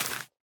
sapling_place5.ogg